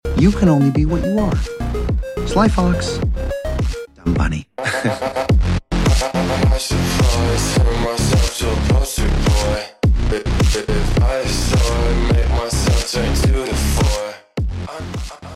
slowed